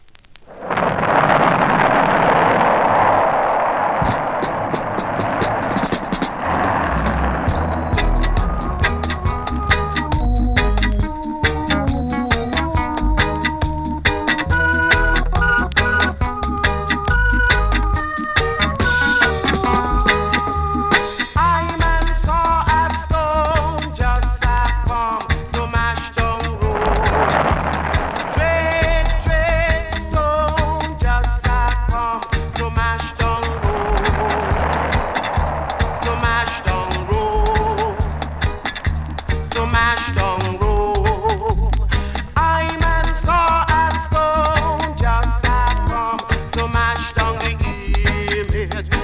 2026!! NEW IN!SKA〜REGGAE
スリキズ、ノイズかなり少なめの